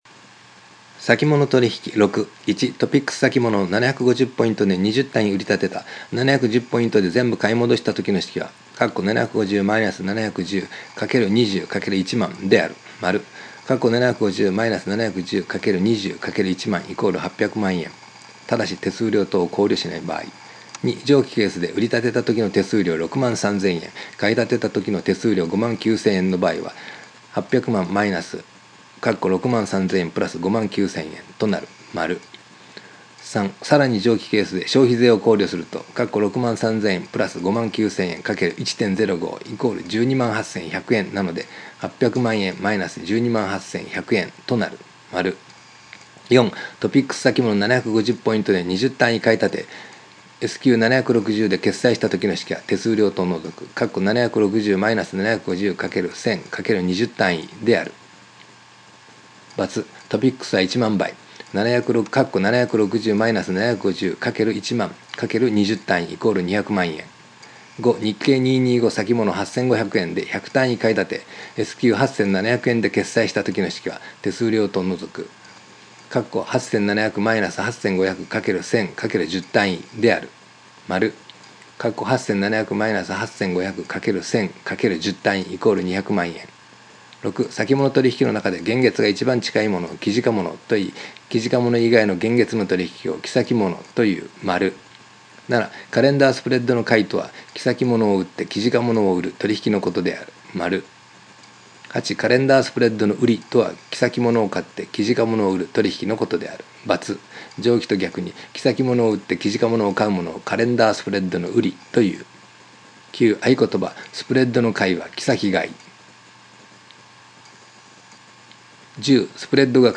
（中年オヤジのダミ声での録音ですが、耳から聴いて覚えたいという方はご活用ください）